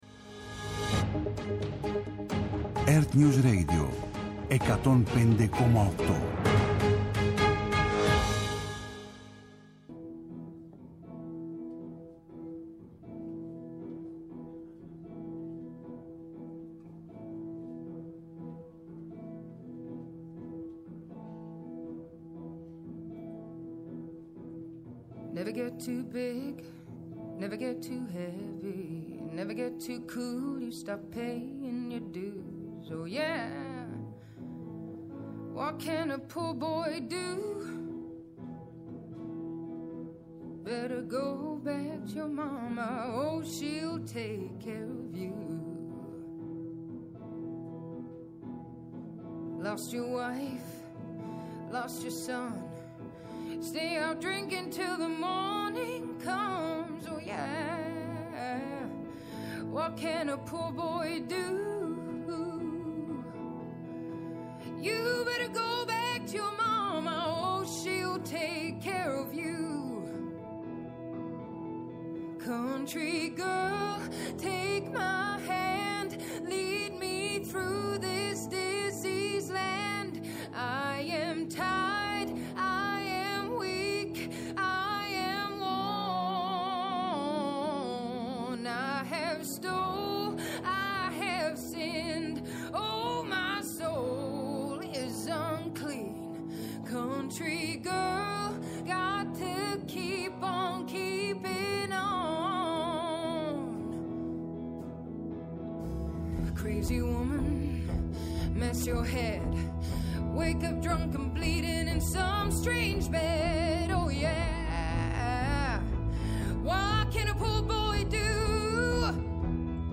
Με τη νέα ραδιοφωνική του εκπομπή «Cine-Πώς», που θα μεταδίδεται κάθε Σάββατο στις 21.00, παρουσιάζει την cine-επικαιρότητα και επιχειρεί να λύσει – ή έστω να συζητήσει – τις κινηματογραφικές μας απορίες.